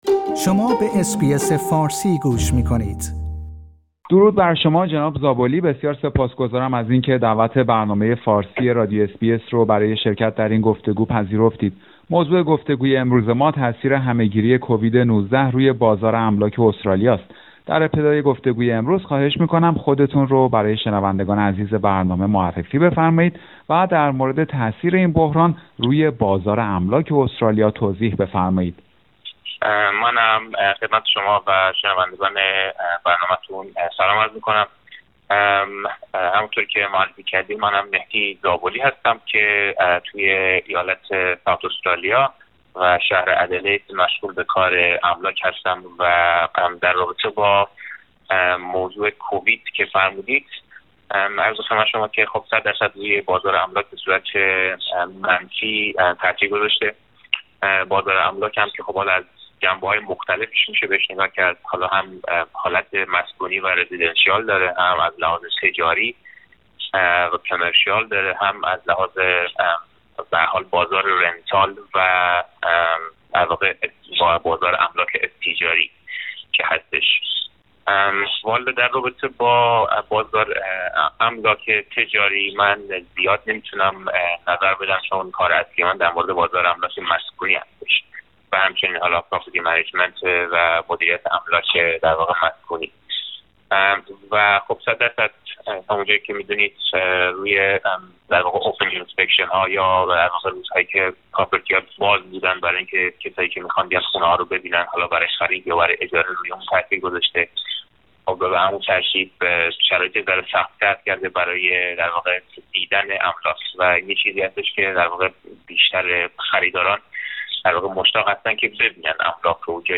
گفتگو با یک کارشناس املاک در مورد تاثیر همه گیری کووید-۱۹ روی بازار املاک استرالیا
همه گیری کووید-۱۹ تاثیرات عمیقی روی اقتصاد استرالیا، از جمله بازار املاک این کشور داشته است. در همین خصوص، برنامه فارسی رادیو اس بی اس گفتگویی داشته با یک کارشناس رسمی املاک و به بررسی وضعیت کلی بازار املاک استرالیا پرداخته است.